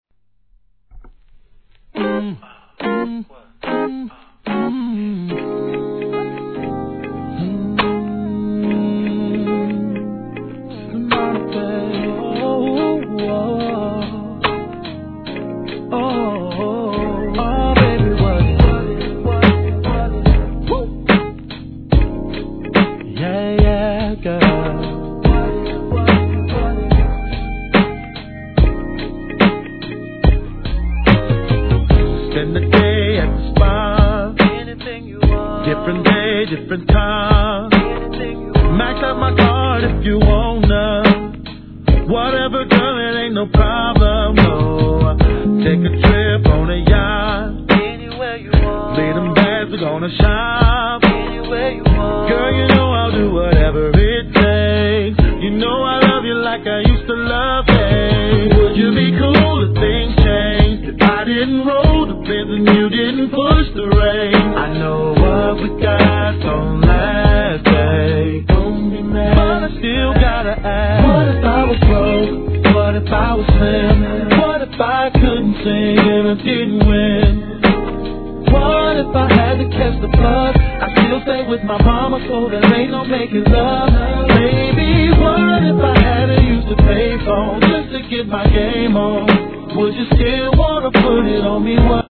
HIP HOP/R&B
奥深く、それでいて優しいヴォーカルでドラマチックに歌い上げる絶品R&B!!!